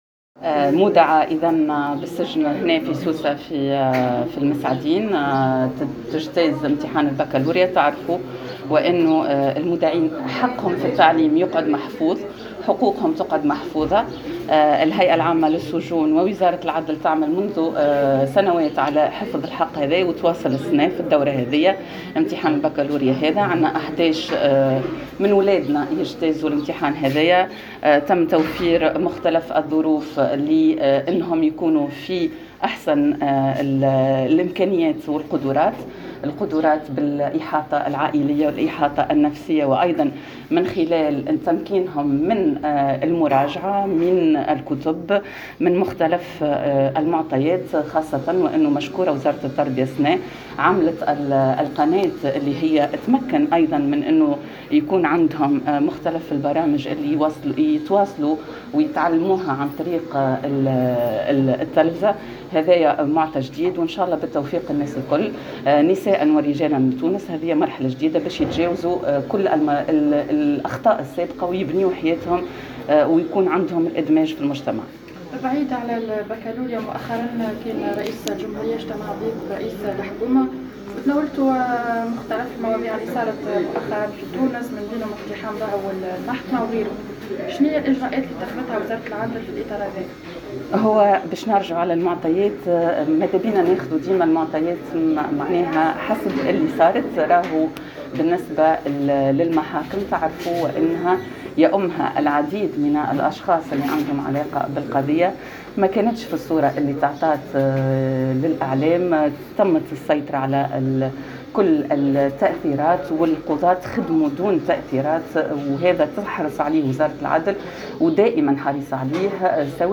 La ministre de la Justice par intérim, Hasna Ben Slimane s’est rendu ce matin à la prison civile de Messadine dans le gouvernorat de Sousse afin de superviser le bon déroulement de la première épreuve de cet examen national.
Hasna Ben Slimane a annoncé dans une déclaration accordée à Tunisie Numérique que, pour la première fois en Tunisie, une détenue passe les épreuves du baccalauréat.